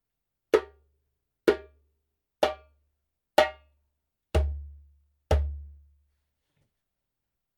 Djembe made in Senegal
Wood : Dimb ディンブ
低くよく響くベース音、軽量ながらキレと芯のある音に仕上がりました。
ジャンベ音